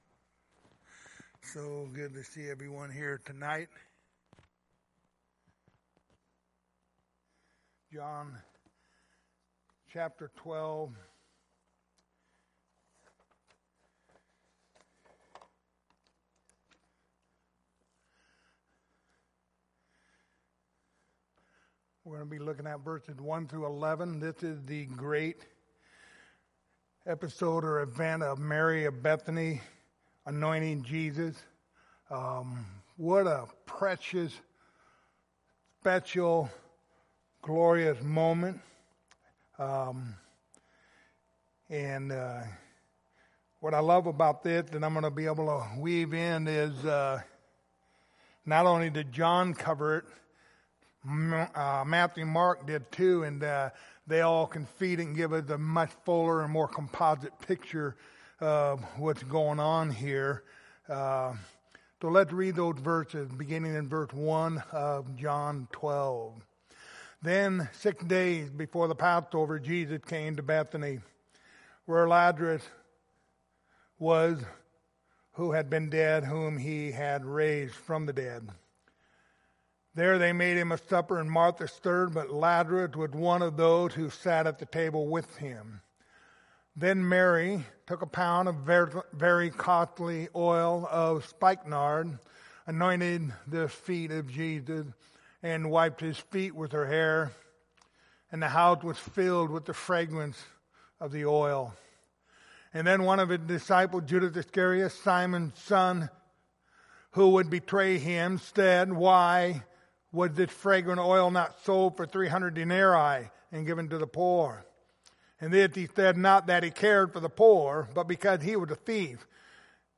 Passage: John 12:1-11 Service Type: Wednesday Evening Topics